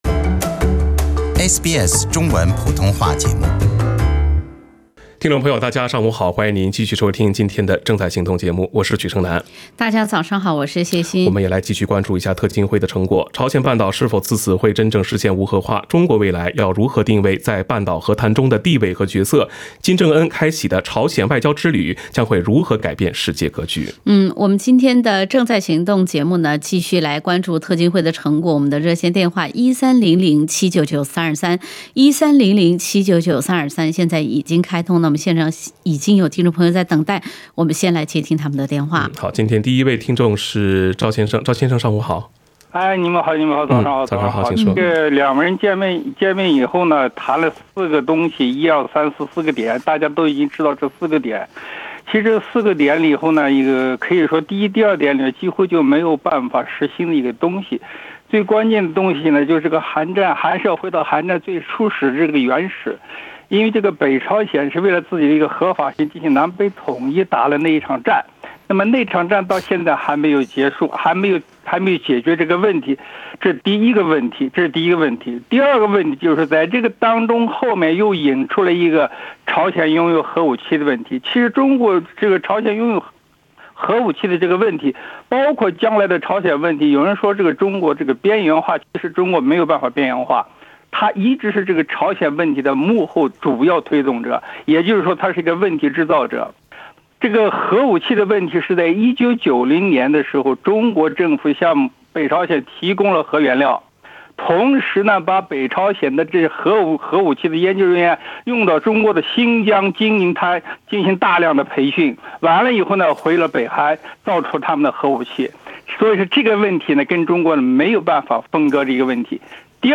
时政热线节目《正在行动》逢周三上午8点30分至9点播出。